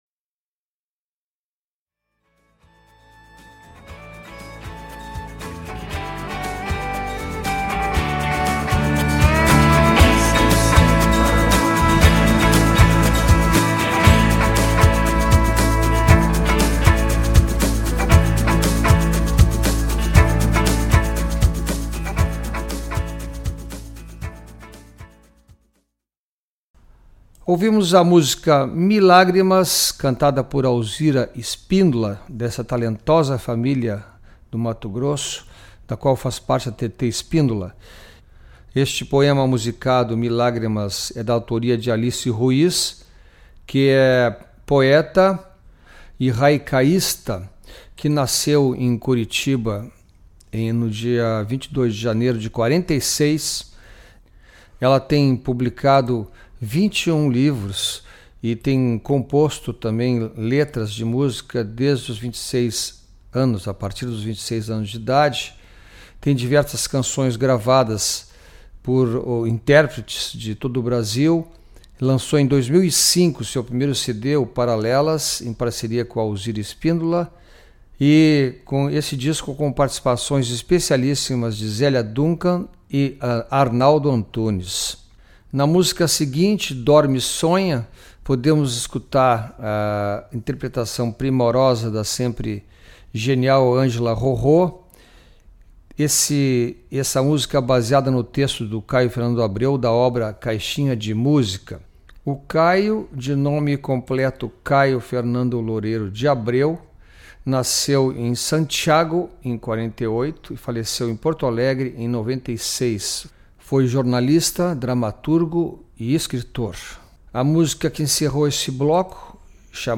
O Sul Em Cima Especial dessa edição é um programa dedicado a música e a literatura. Vamos apresentar escritores e poetas do RS, PR e Argentina que tiveram seus textos musicados.